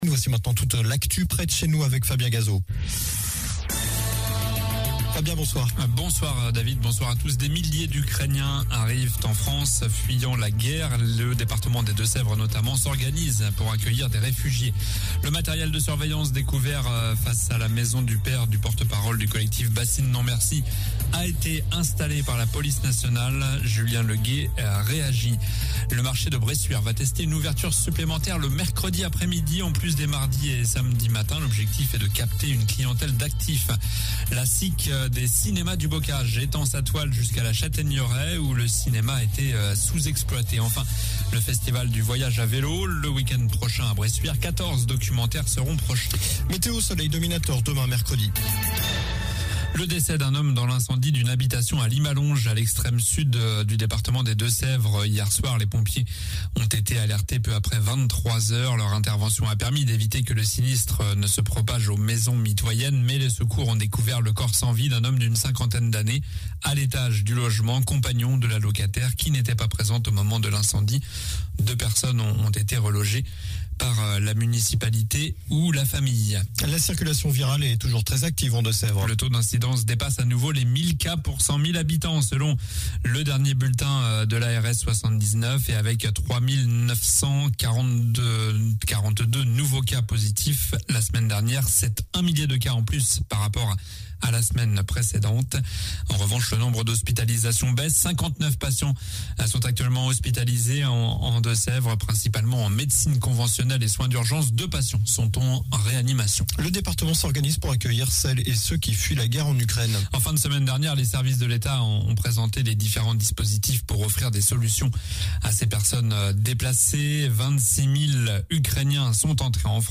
Journal du mardi 22 mars (soir)